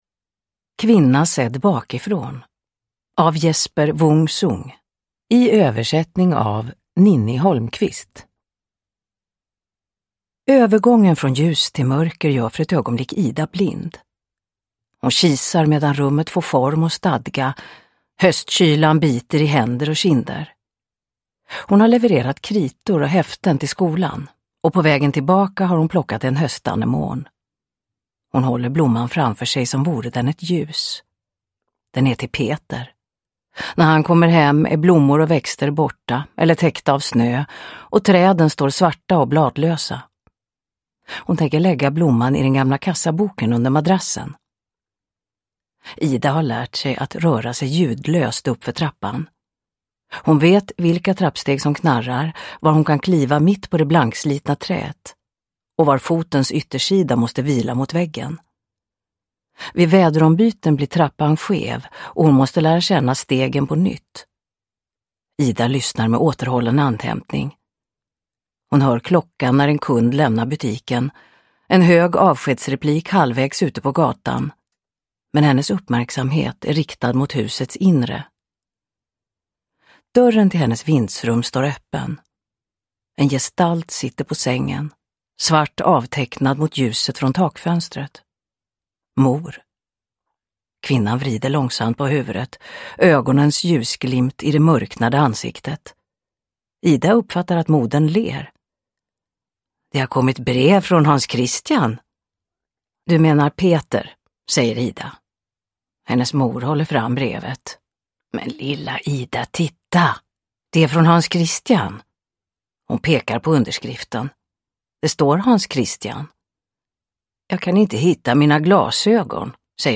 Kvinna sedd bakifrån – Ljudbok – Laddas ner
Uppläsare: Marie Richardson